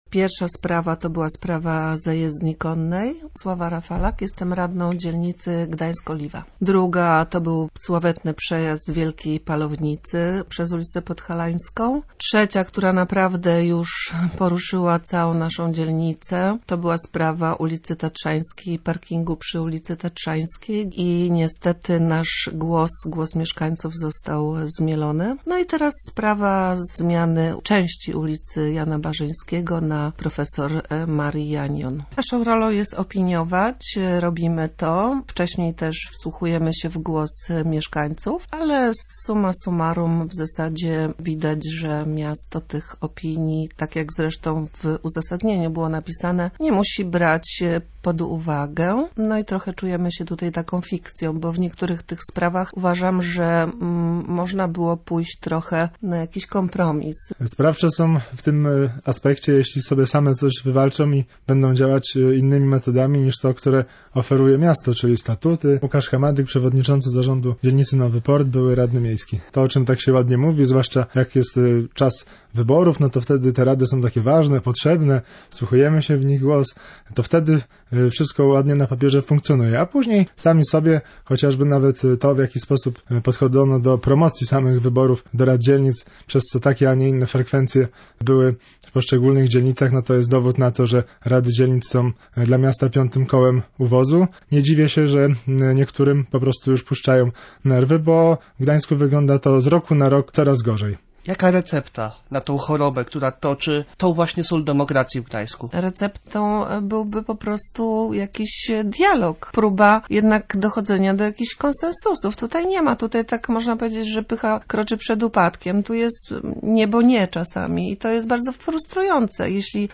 Posłuchaj materiału reportera: https